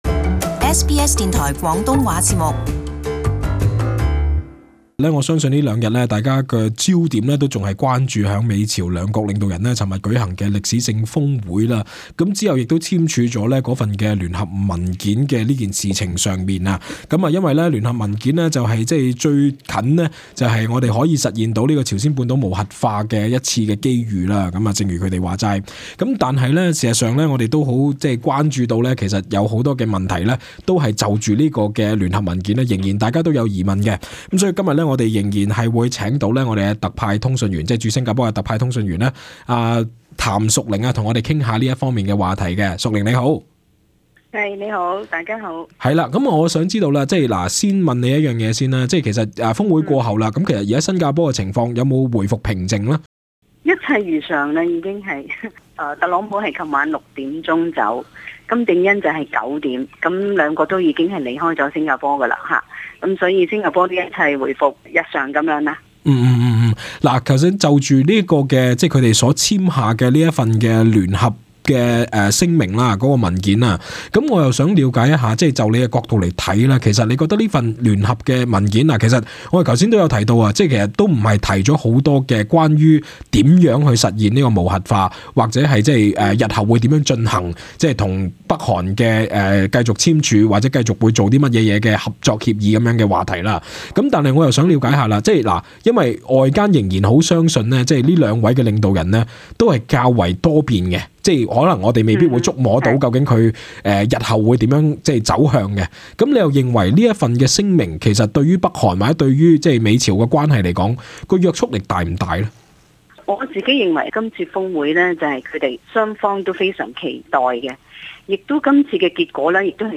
【時事專訪】美朝峰會開啟朝鮮半島無核化